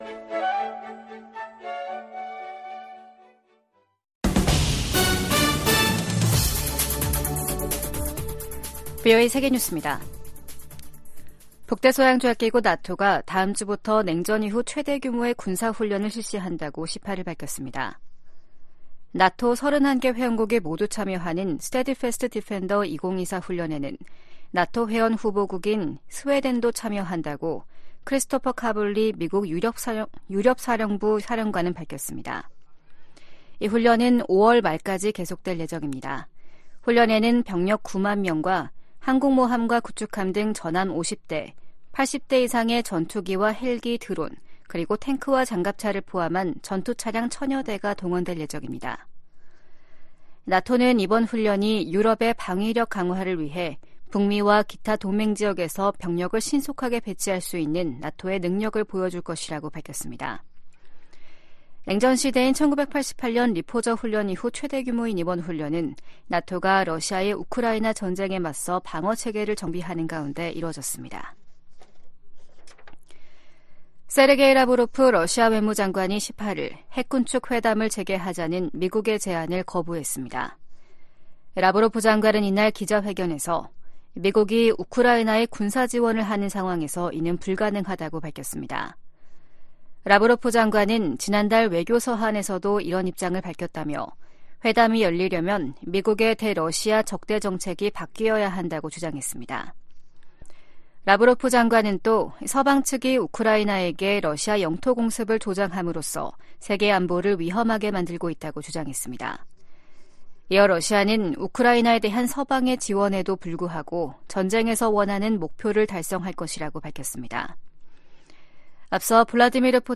VOA 한국어 아침 뉴스 프로그램 '워싱턴 뉴스 광장' 2024년 1월 19일 방송입니다. 미국은 북한과 러시아의 무기 거래는 안보리 결의 위반이라고 비판하고, 북한 지도부에 외교에 복귀하라고 촉구했습니다. 미한일 북 핵 수석대표들이 북한에 긴장을 고조시키는 언행과 도발, 무모한 핵과 미사일 개발을 중단할 것을 요구했습니다. 국제 기독교선교단체 '오픈도어스'가 2024 세계 기독교 감시 보고서에서 북한을 기독교 박해가 가장 극심한 나라로 지목했습니다.